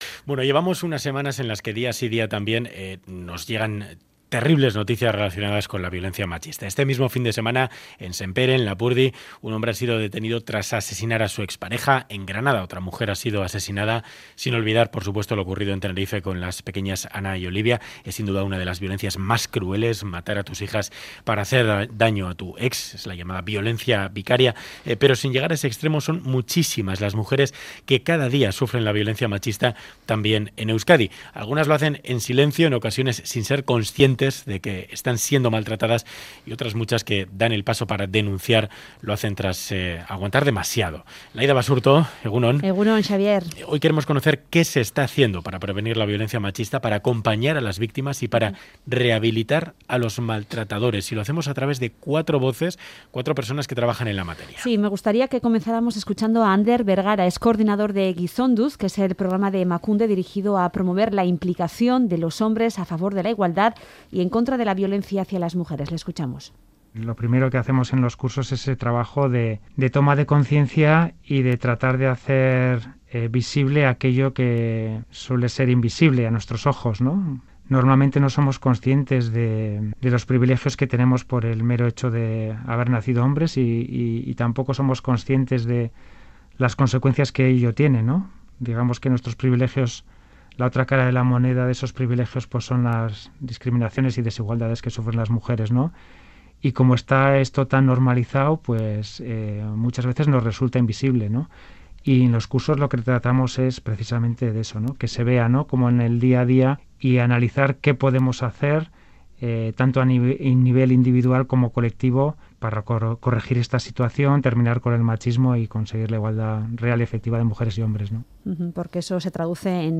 Audio: Reportaje violencia machista cómo prevenir rehabilitación maltratadores